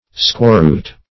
Squawroot \Squaw"root`\ (skw[add]"r[=oo]t`), n. (Bot.)